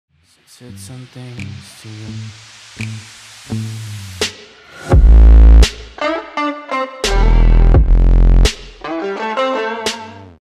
twitchhitbox-followdonation-sound_pVRMGi3.mp3